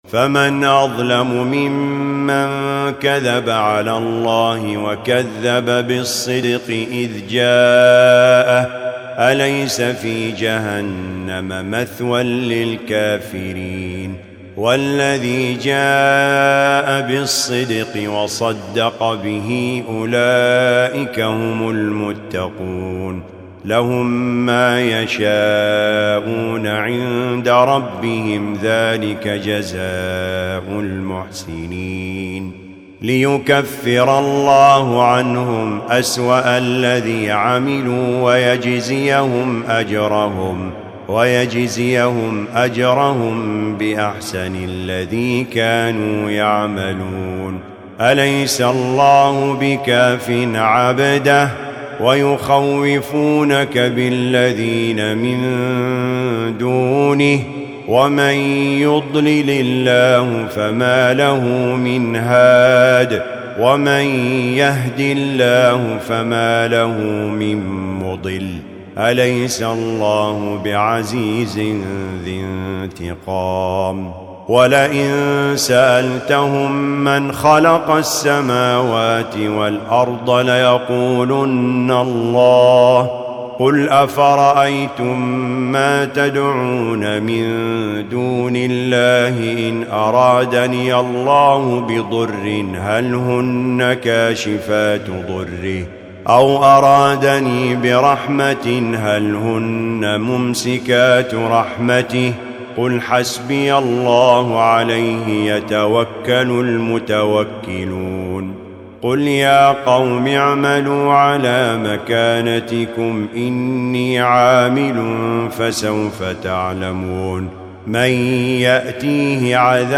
الجزء الرابع و العشرون : سور الزمر 31-75 و غافر و فصلت 1-46 > المصحف المرتل